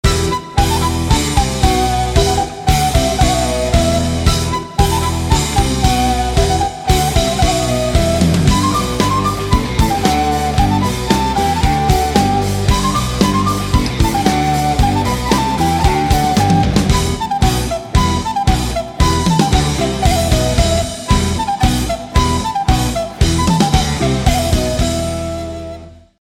Флейта и Элеткрогитара